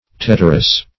Tetterous \Tet"ter*ous\, a.